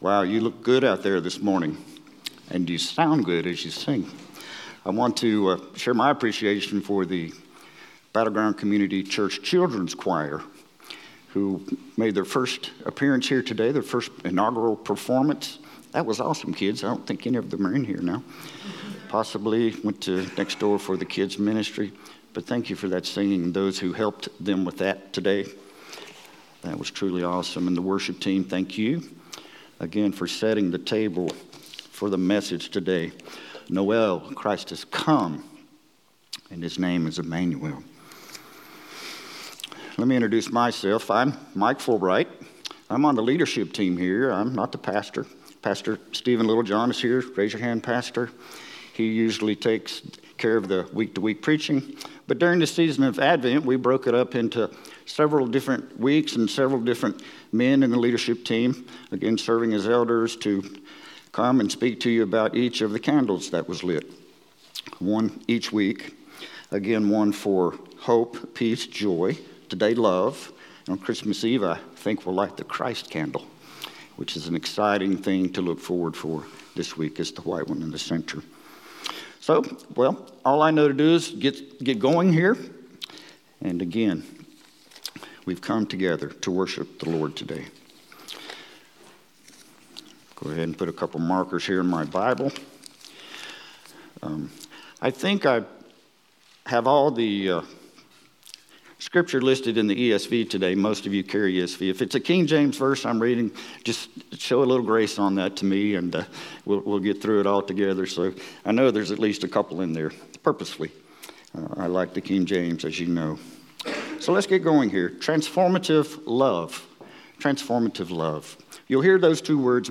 Battleground Community Church Sermons